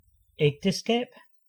Ääntäminen
IPA: /ma.ʁjaʒ/